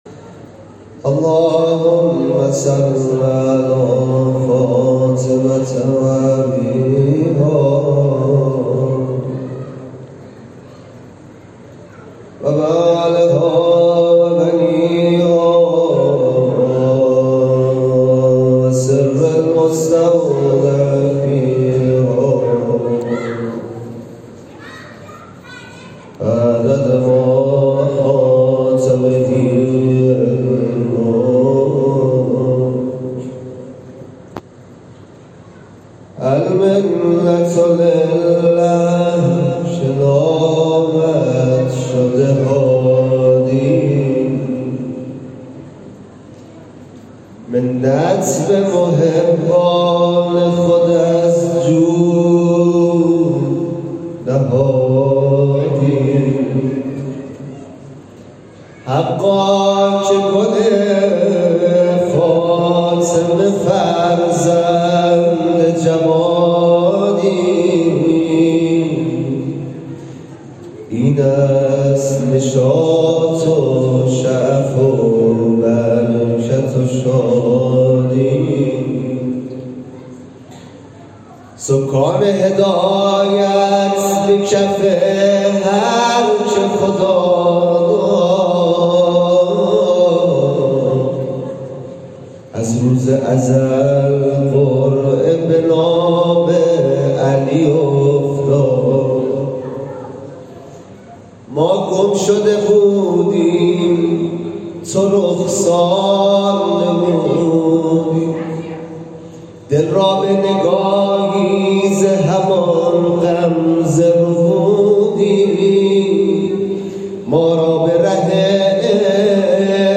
صوت | مولودی به مناسبت ولادت امام هادی(ع)
در مراسم ولادت این امام همام در مسجد امام هادی(ع) شهرک منظریه
برچسب ها: ولادت امام هادی (ع) ، مداحی ، جامعه کبیره ، امام دهم